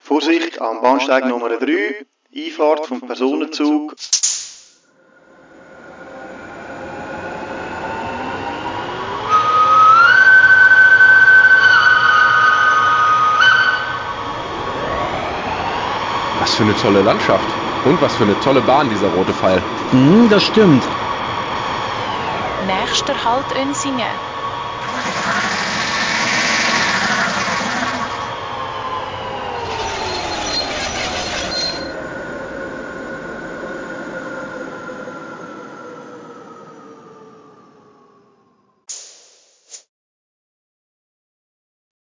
Märklin 38860 Demo-Sound.mp3